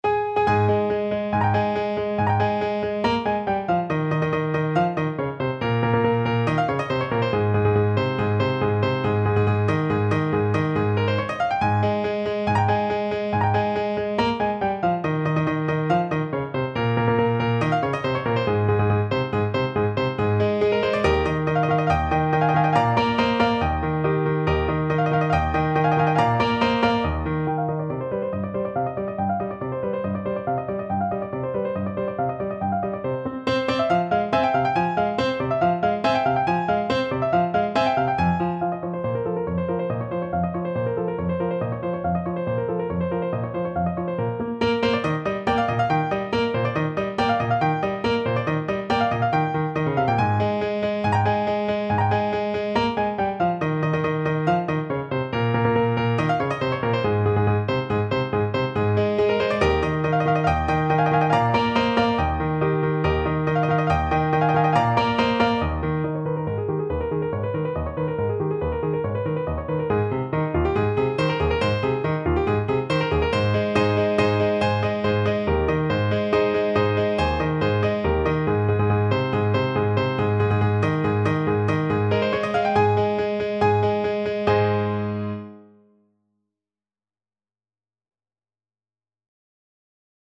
~ = 140 Allegro vivace (View more music marked Allegro)
2/4 (View more 2/4 Music)
Classical (View more Classical Saxophone Music)